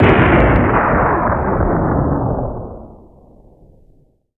explo1.wav